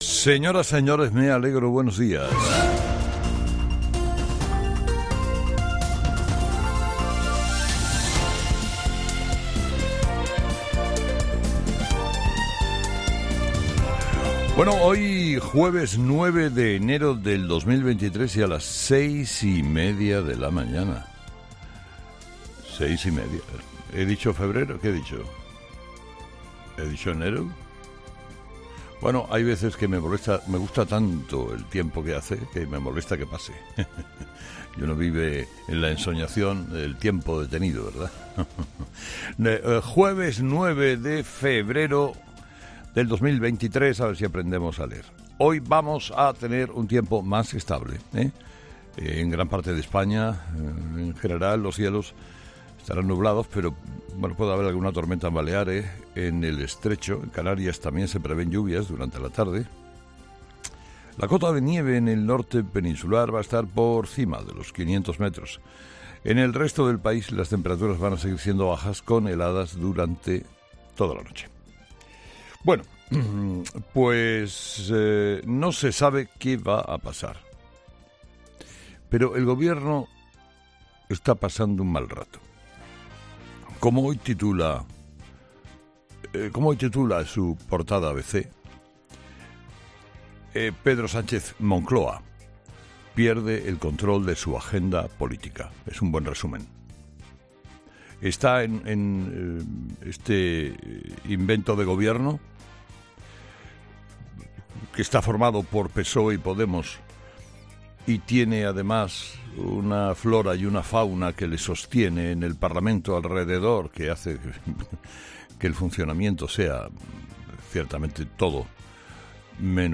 Carlos Herrera, director y presentador de 'Herrera en COPE', comienza el programa de este miércoles analizando las principales claves de la jornada, que pasan, entre otros asuntos, por la defensa de Pedro Sánchez a las decisiones tomadas en torno a la ley del 'solo sí es sí' delante del Congreso.